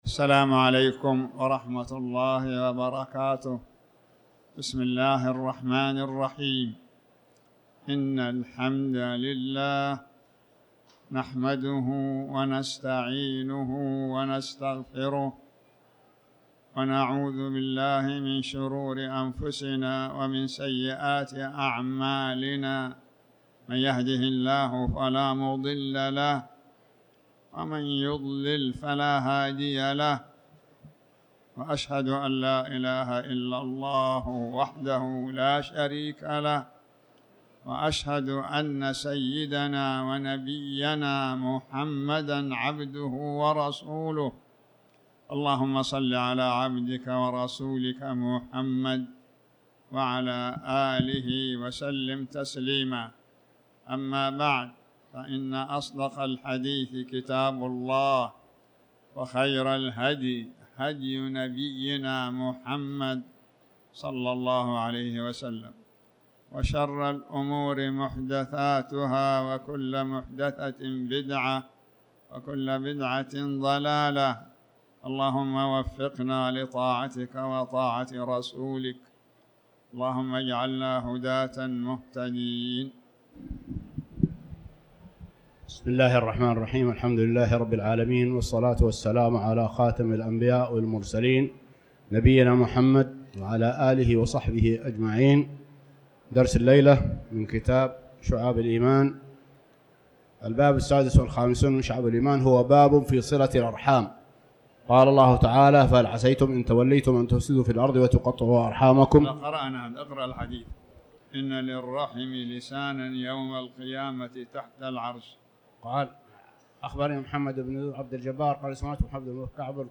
تاريخ النشر ١٨ ربيع الثاني ١٤٤٠ هـ المكان: المسجد الحرام الشيخ